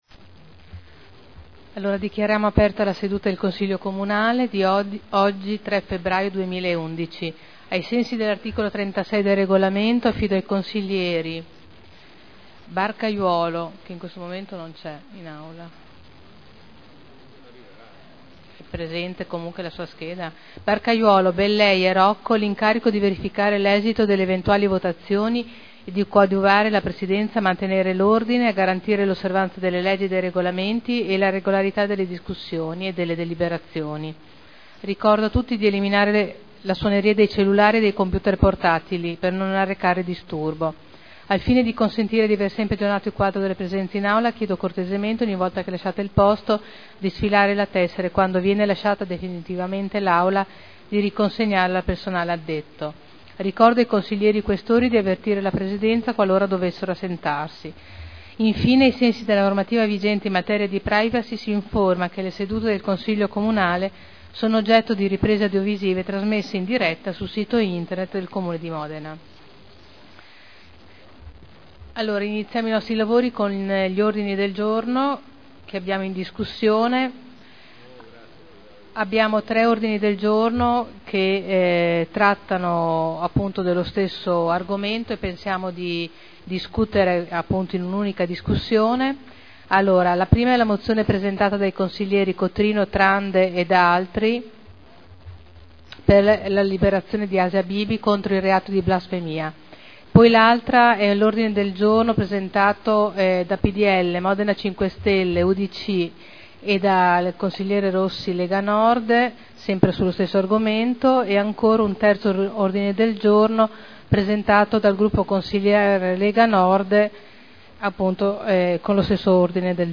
Presidente — Sito Audio Consiglio Comunale
Seduta del 3/02/2011. Apertura Consiglio Comunale